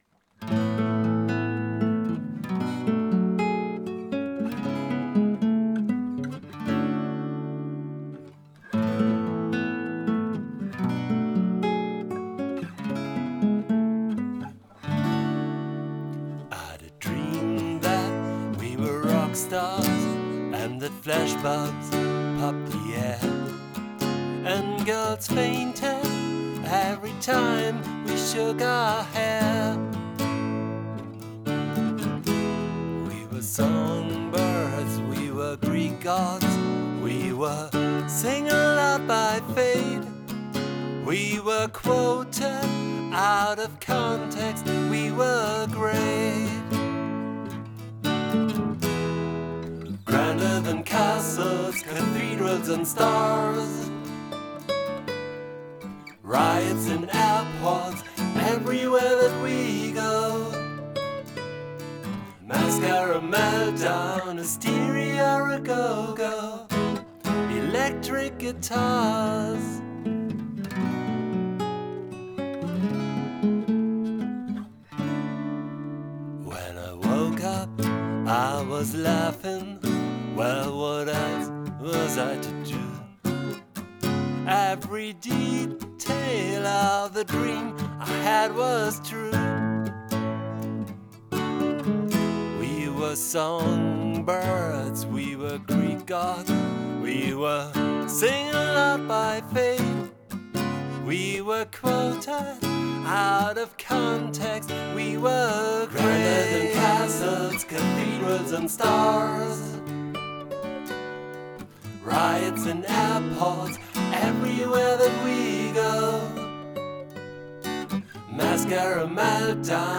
A nylon guitar, one acoustic and some vocal tracks.